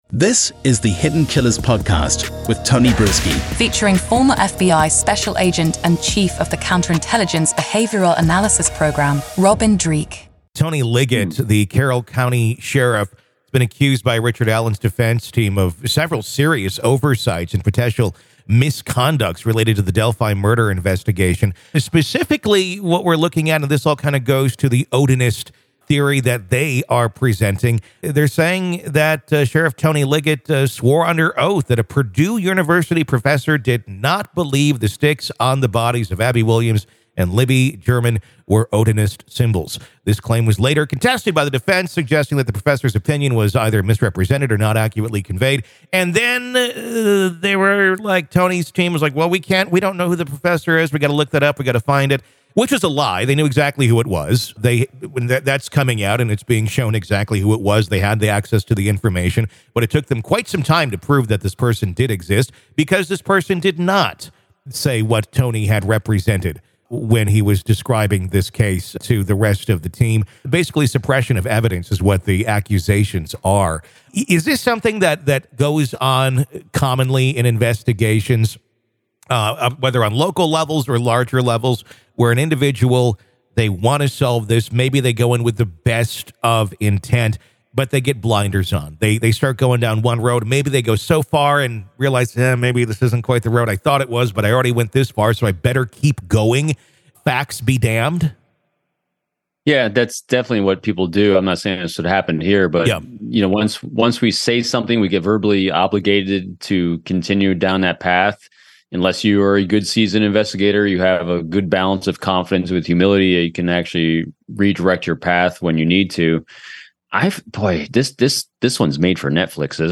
In an era where true crime dominates headlines and TV screens, the conversation between